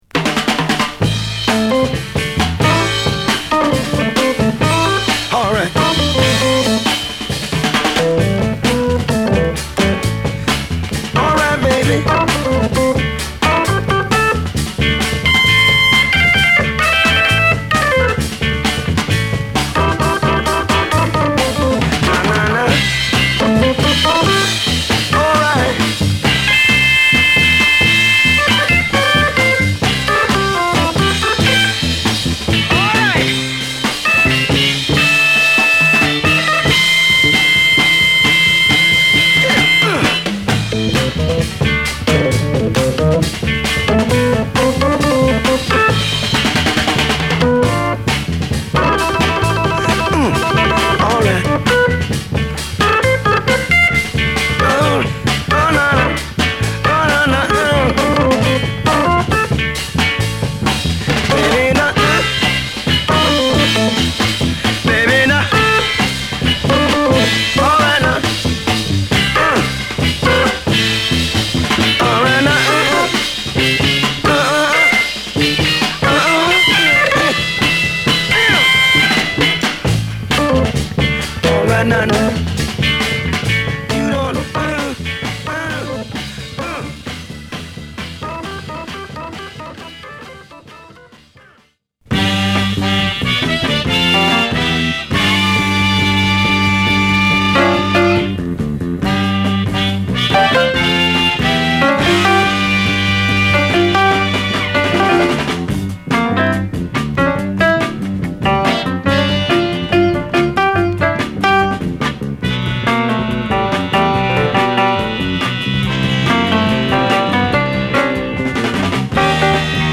疾走感満点のラグドなドラムにファンキーなオルガンがこれでもかと唸りを上げる、熱いファンキーチューン！